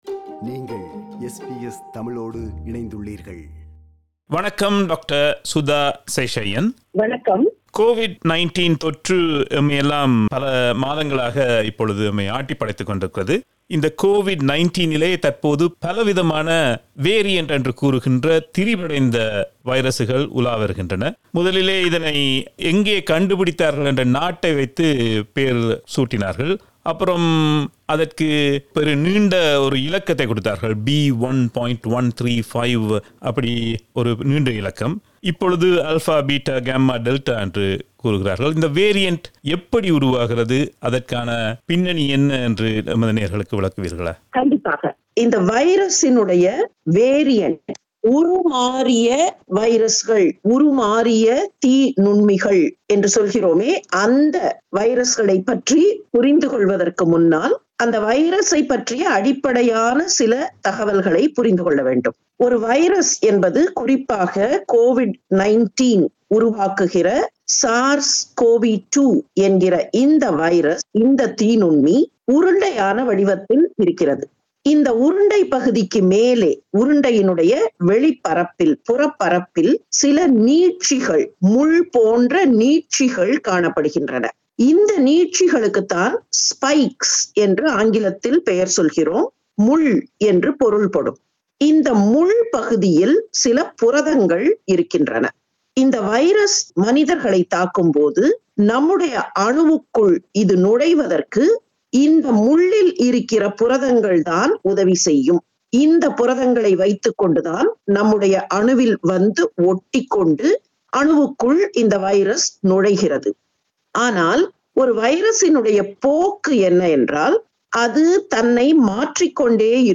In the first of the two-part interview